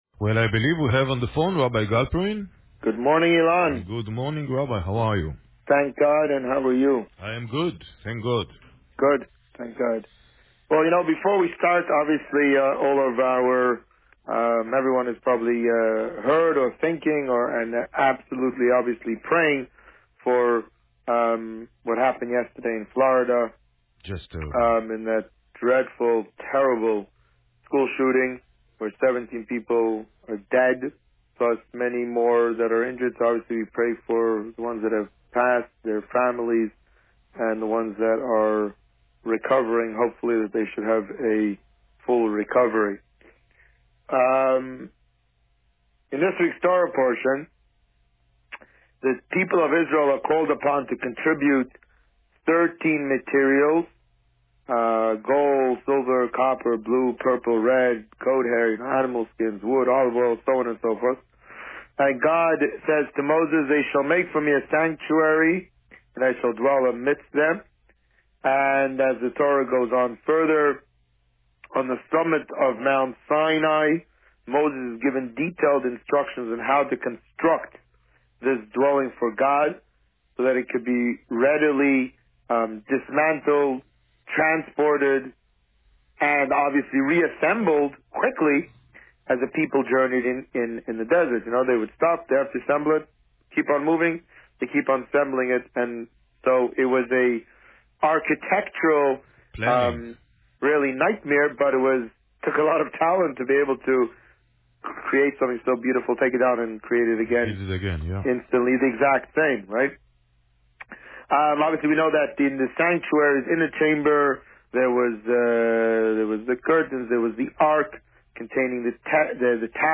This week, the Rabbi spoke about Parsha Terumah and the upcoming Purim party. Listen to the interview here.